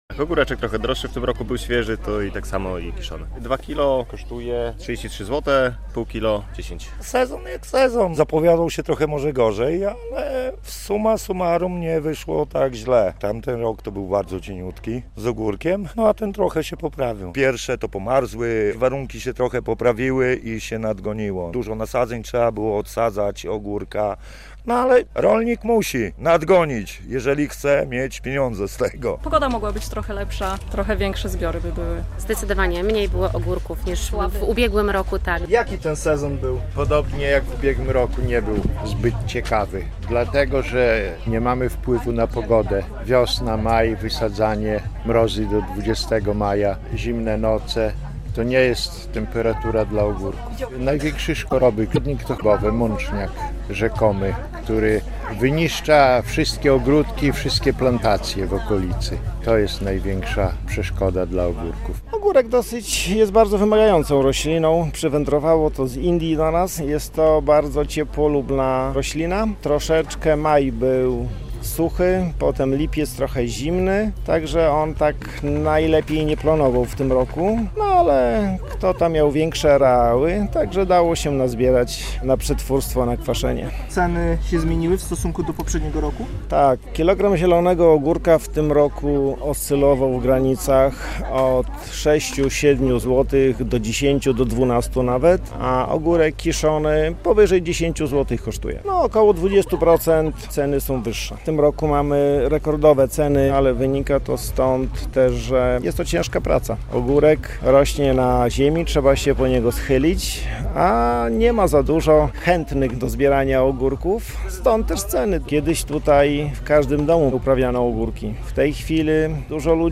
W niedzielę (7.09) odbył się tam po raz 26. Dzień Ogórka. Była to okazja do zapytania producentów tych warzyw o ceny, ale też o cały sezon.
Sezon ogórkowy w Kruszewie - relacja